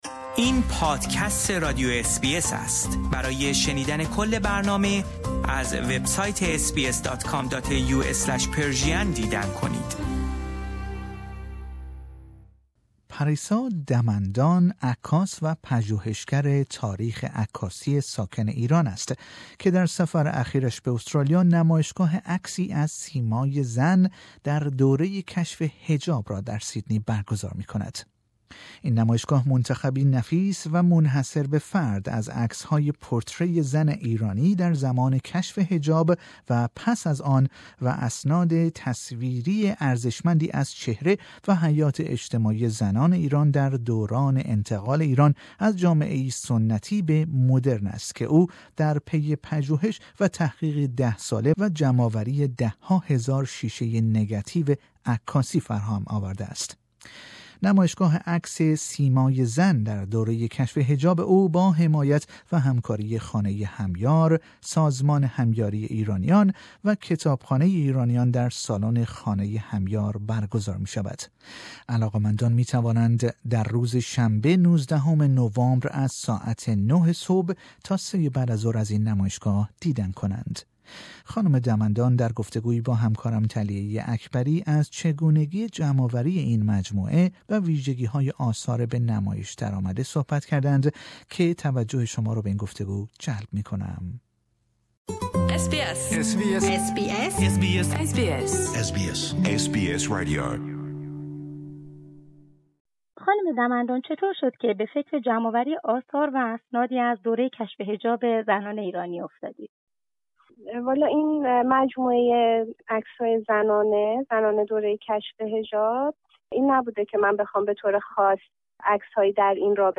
گفتگویی کوتاه با بخش فارسی رادیو اس بی اس